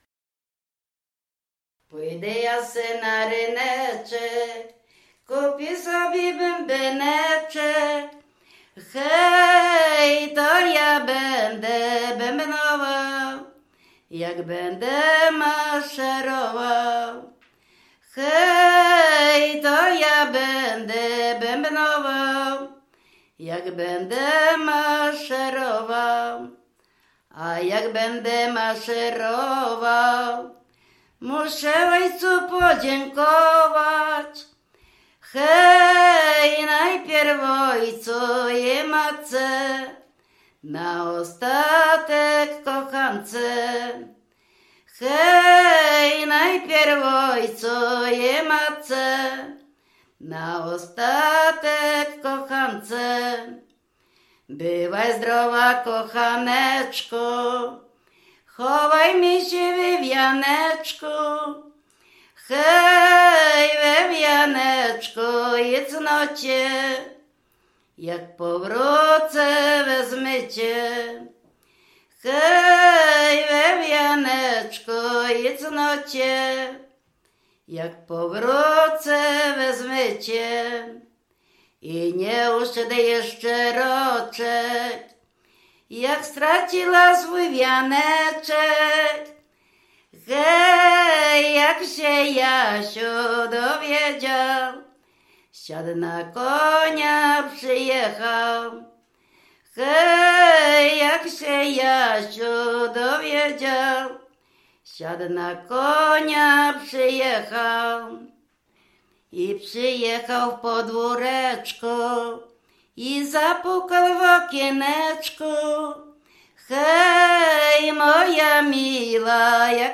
W wymowie Ł wymawiane jako przedniojęzykowo-zębowe;
rekruckie wojenkowe